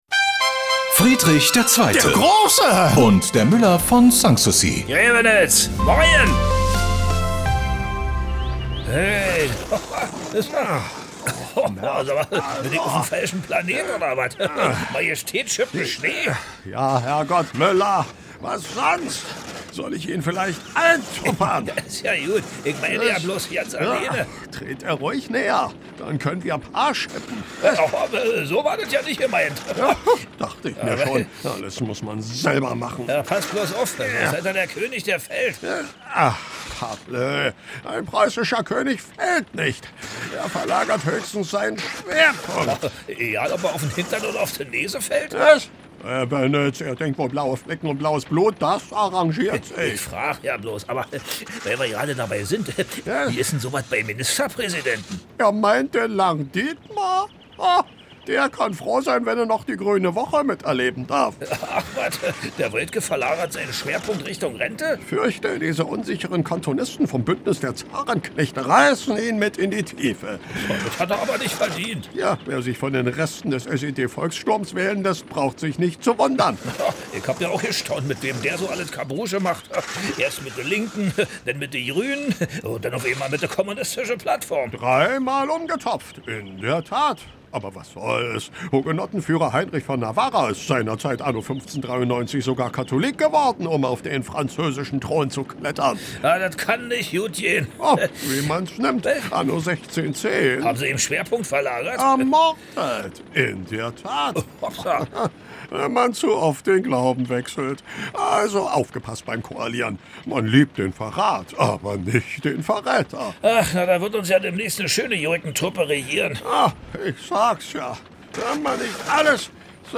Regionales , Comedy , Radio